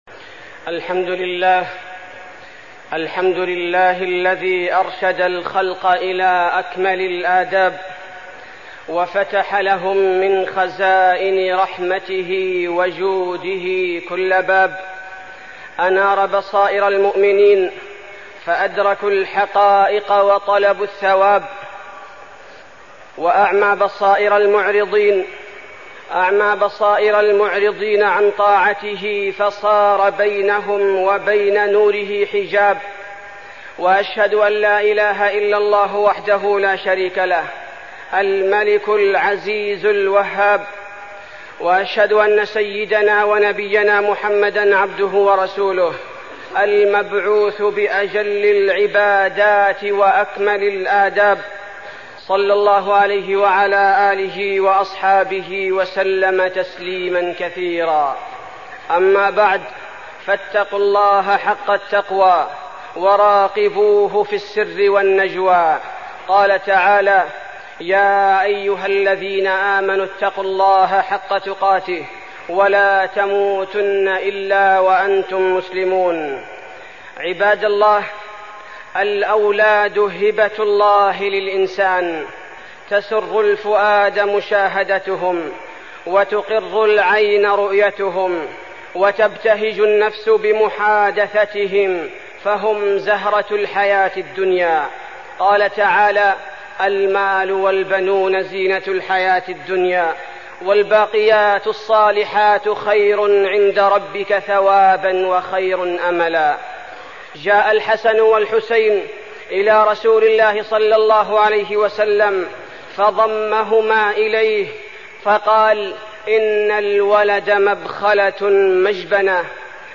تاريخ النشر ١٤ محرم ١٤١٧ هـ المكان: المسجد النبوي الشيخ: فضيلة الشيخ عبدالباري الثبيتي فضيلة الشيخ عبدالباري الثبيتي تربية الأبناء